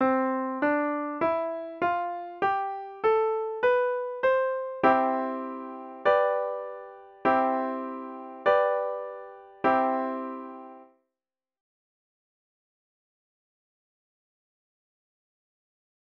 You will hear the C Scale, then the chord progression C-G-C-G-C. Listen to how G resolves to C, creating balance and resolution.
G resolving to C
G-major-chord-dominant.mp3